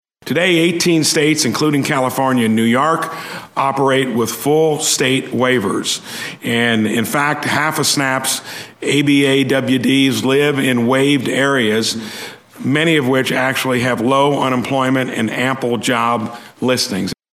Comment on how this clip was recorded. Partisan differences over limiting Supplemental Nutrition Assistance Program (SNAP) spending, which makes up more than 80% of the cost attributed to the federal farm bill, were on full display during a Senate Ag subcommittee hearing.